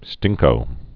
(stĭngkō)